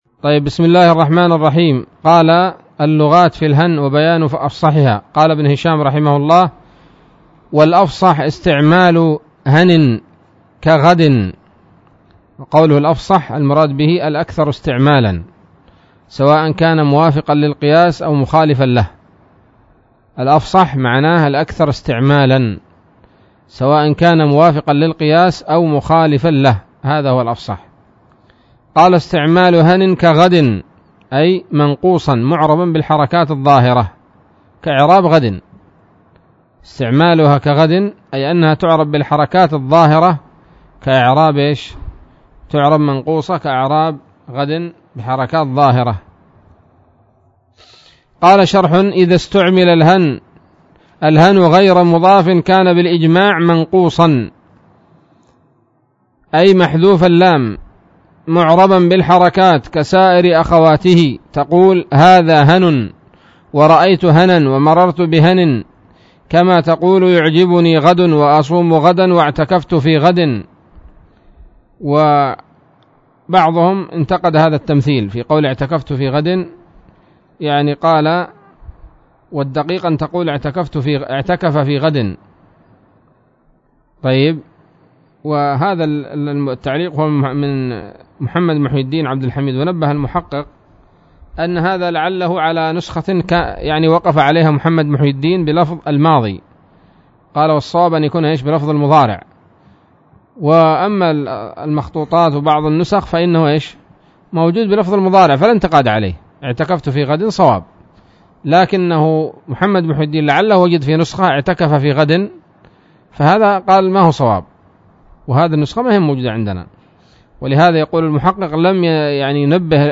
الدرس التاسع عشر من شرح قطر الندى وبل الصدى